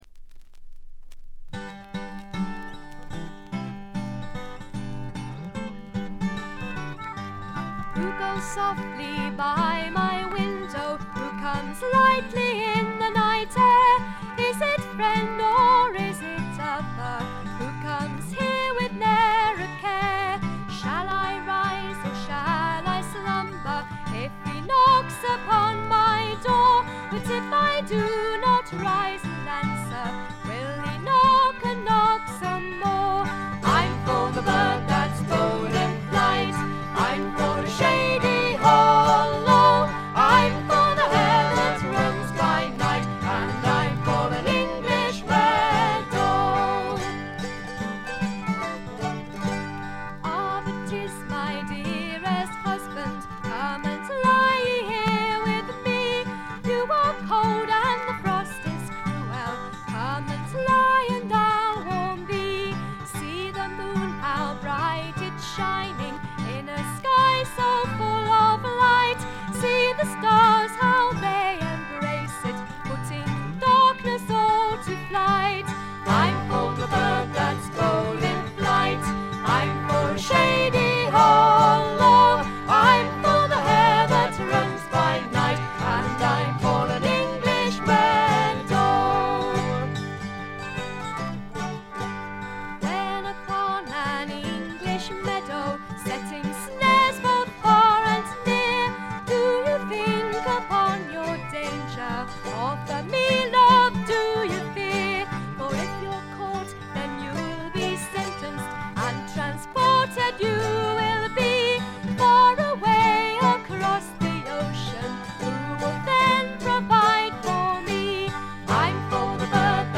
バックグラウンドノイズ、チリプチ多め大きめ。プツ音少々。
すべて自作の曲をまるでトラッドのように演奏しています。
試聴曲は現品からの取り込み音源です。